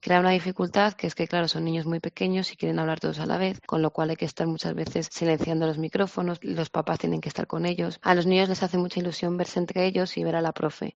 profesora de Infantil